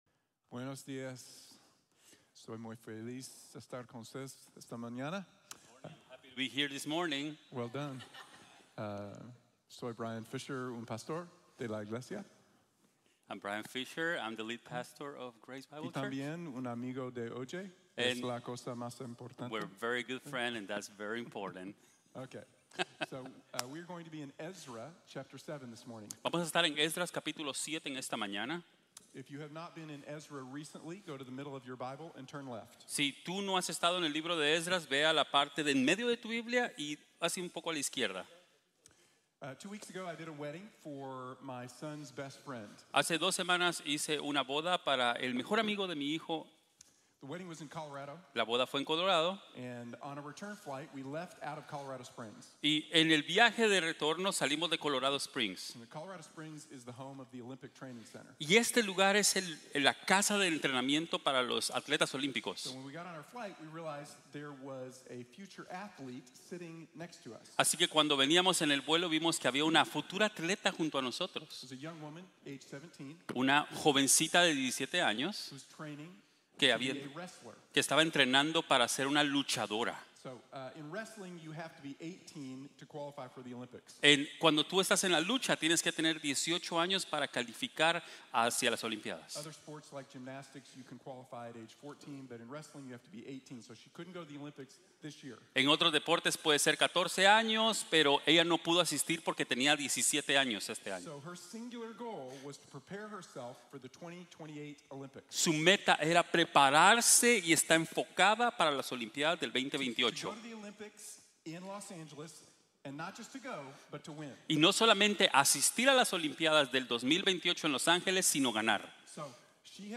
Prepara tu corazón para cumplir tu vocación | Sermon | Grace Bible Church